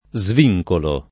DOP: Dizionario di Ortografia e Pronunzia della lingua italiana
svincolo